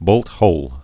(bōlthōl)